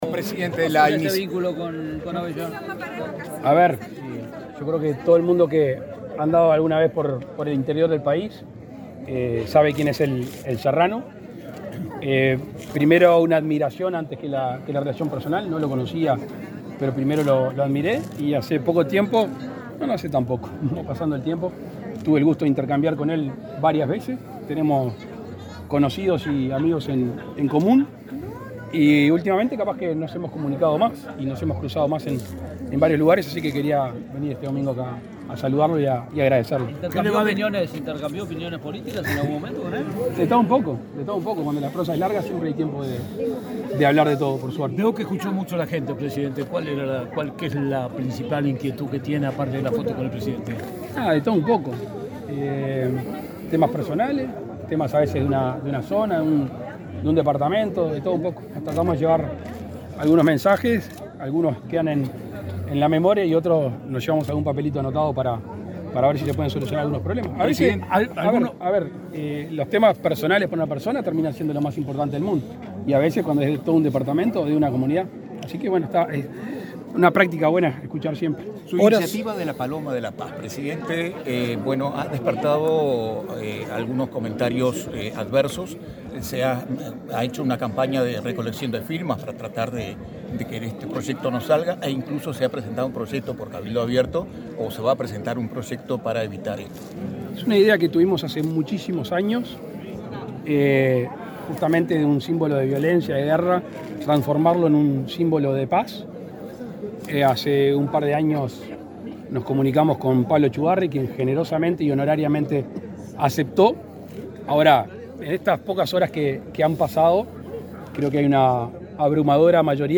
Declaraciones del presidente Lacalle Pou a la prensa
Luego, dialogó con la prensa.